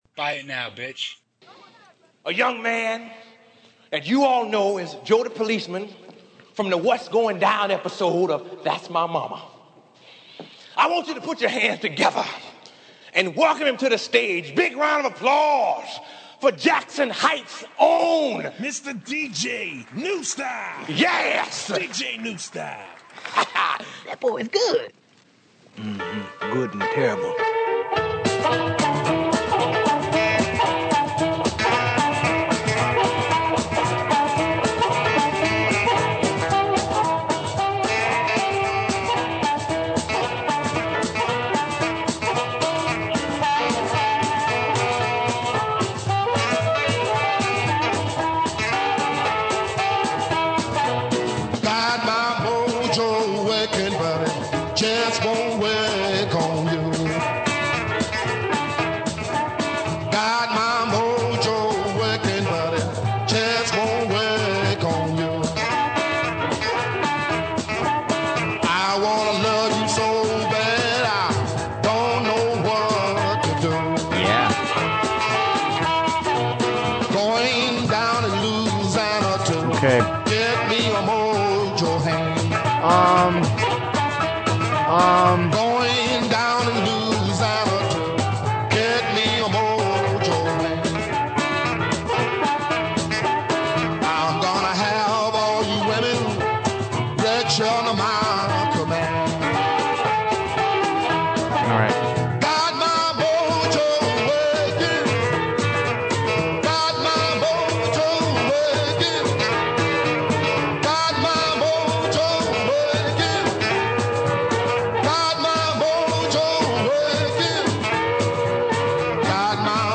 Despite a few technical issues, this was a solid episode.